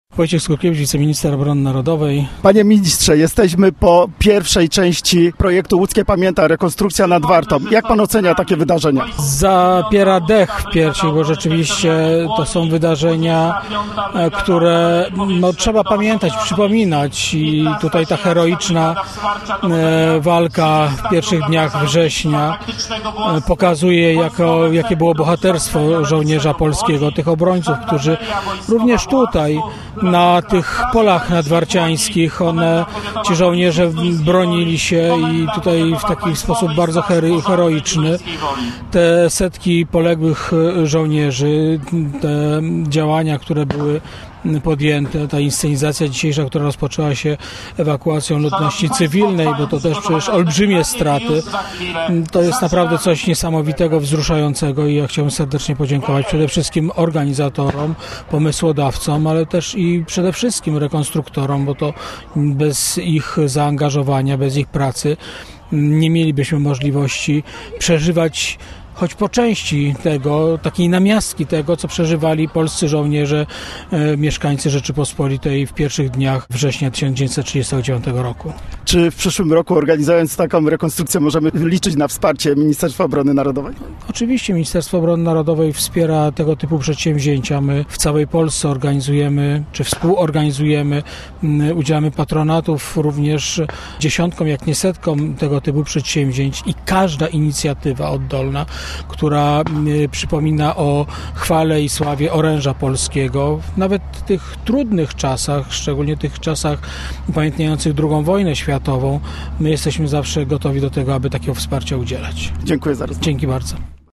Gościem Radia ZW był Wojciech Skurkiewicz, wiceminister MON